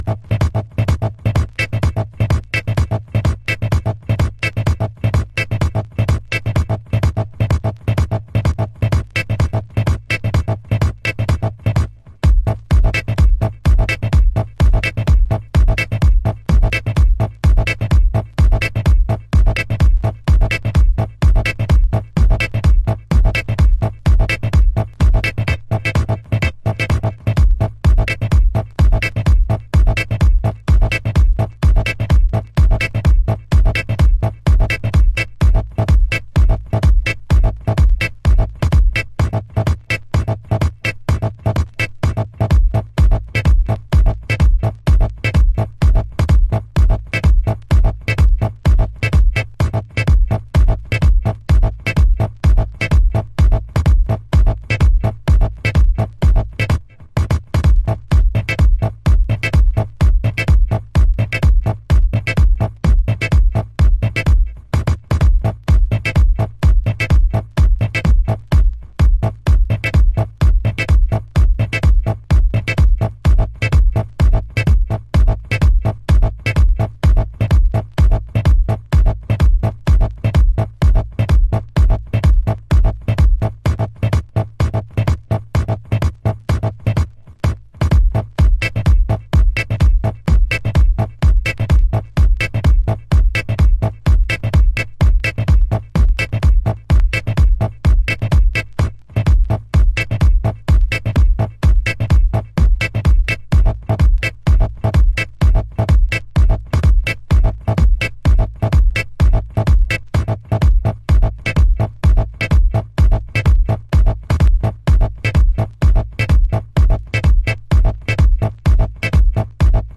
ディスコグルーヴも孕んだデジタル・カットアップテクノ。
House / Techno